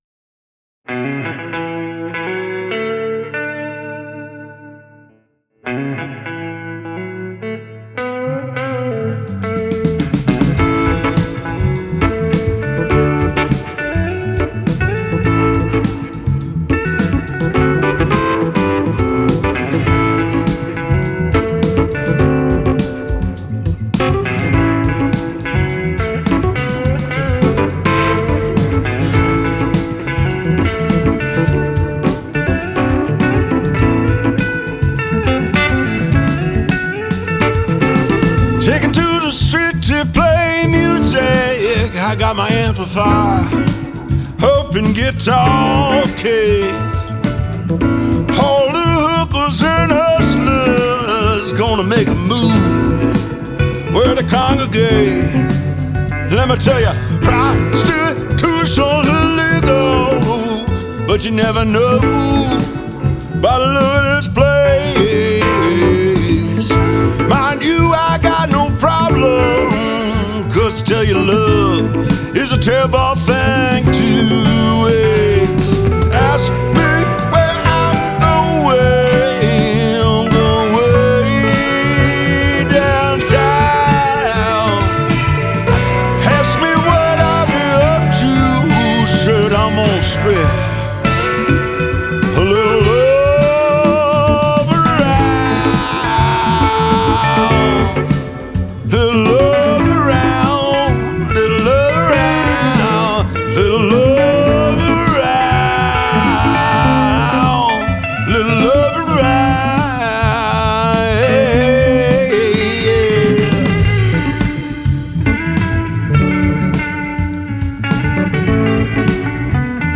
Genre: Rock, Jam Band, Americana, Roots.